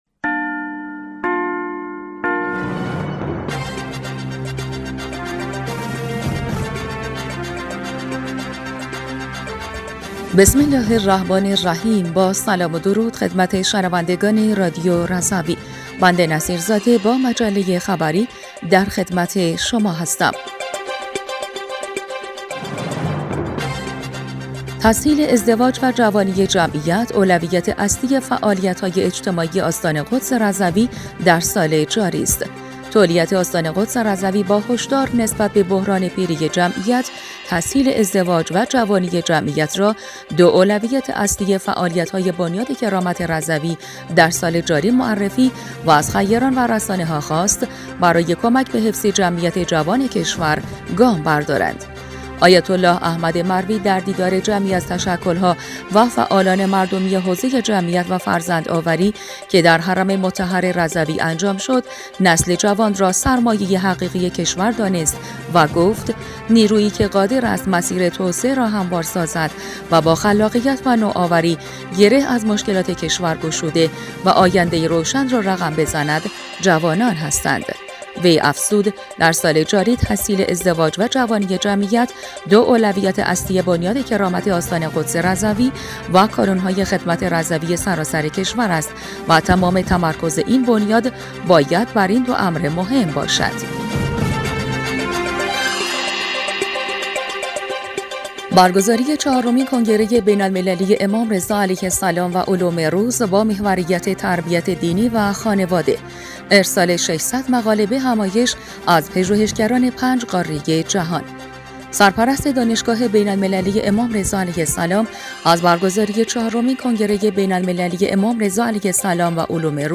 بسته خبری ۵ خرداد ۱۴۰۴ رادیو رضوی/